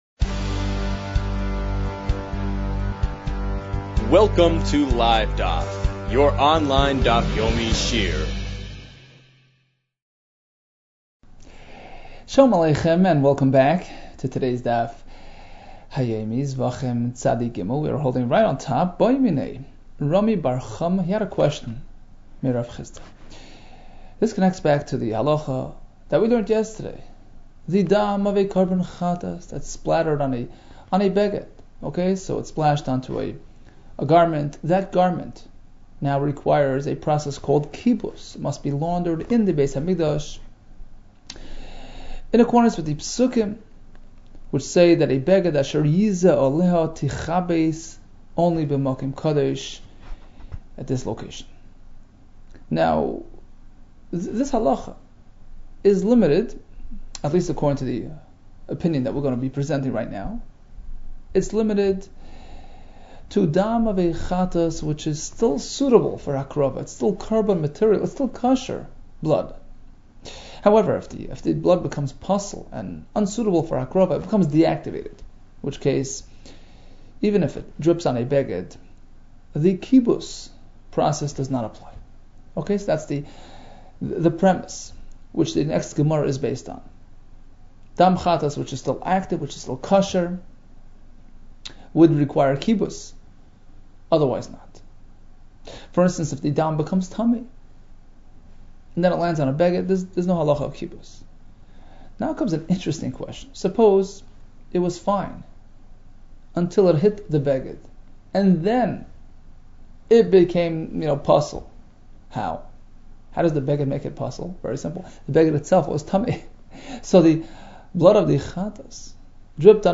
Zevachim 92 - זבחים צב | Daf Yomi Online Shiur | Livedaf